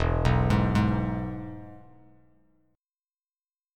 E Chord
Listen to E strummed